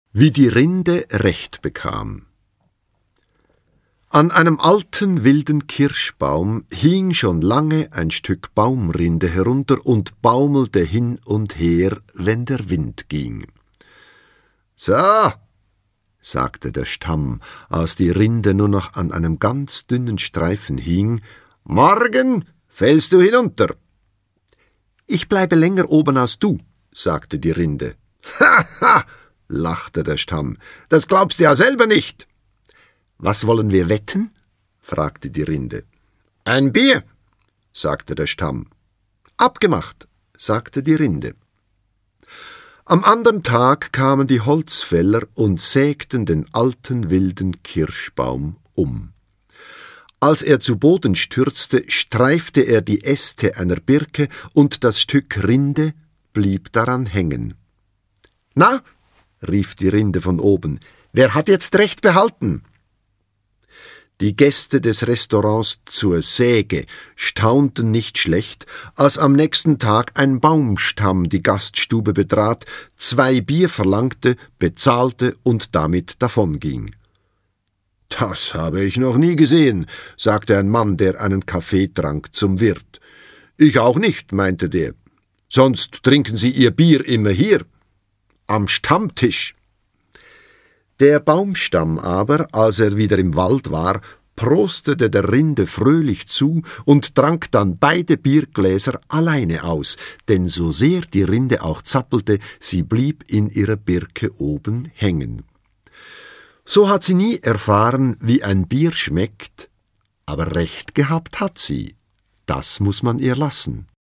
Franz Hohler (Sprecher)
Schlagworte Audio-CD, Kassette / Kinder- und Jugendbücher/Erstlesealter, Vorschulalter • Hörbuch für Kinder/Jugendliche • Hörbuch für Kinder/Jugendliche (Audio-CD) • Hörbuch; Lesung für Kinder/Jugendliche • Kinder-CDs (Audio) • Kinderfragen • Phantasie • Schweiz